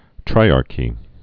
(trīärkē)